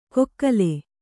♪ kokkale